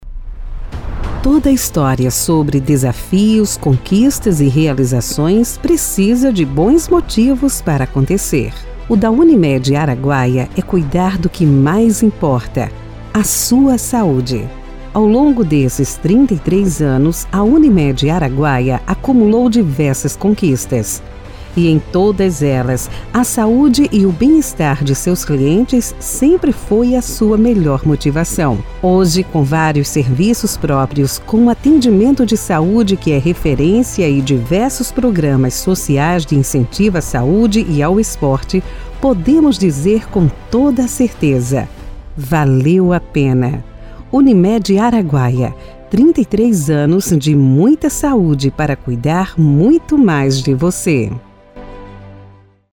LOCUÇÃO INSTITUCIONAL :